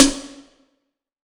SNARE 095.wav